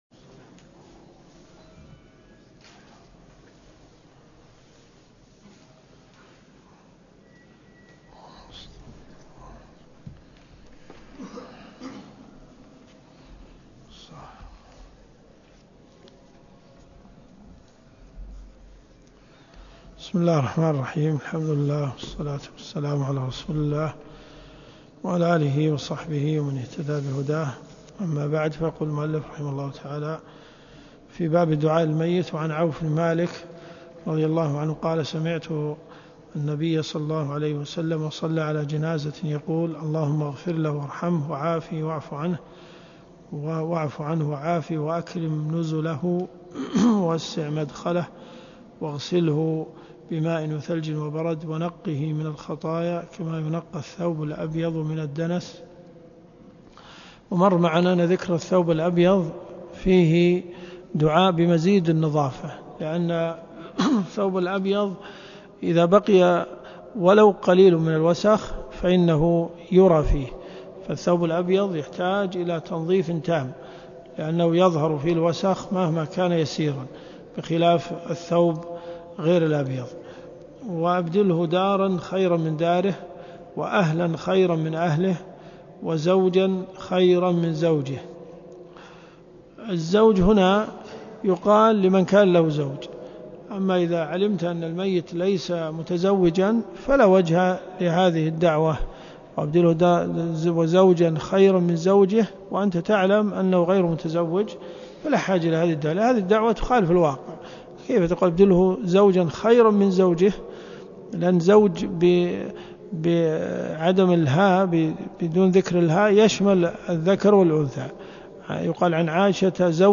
الدروس الشرعية
الرياض . حي أم الحمام . جامع الملك خالد